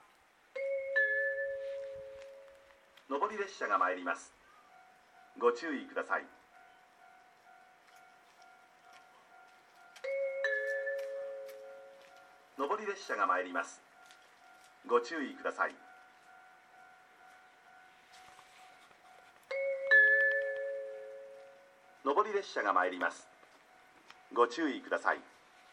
この駅では接近放送が設置されています。
接近放送普通　石巻行き接近放送です。